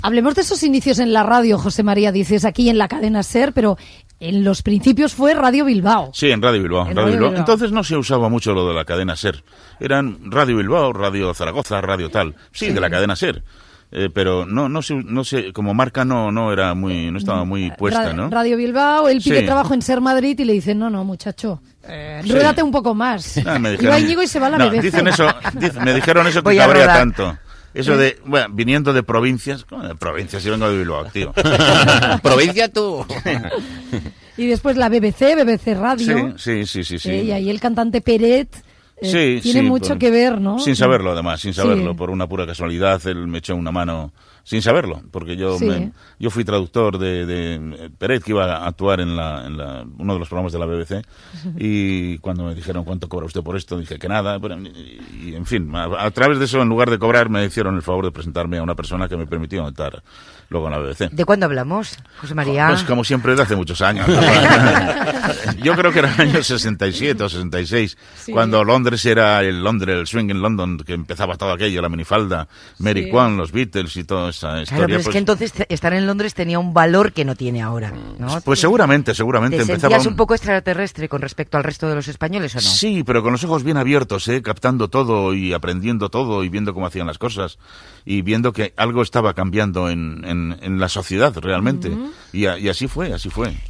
Entrevista al presentador José María Íñigo que parla dels seus inicis a la ràdio
Info-entreteniment